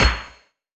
hitBigOther.wav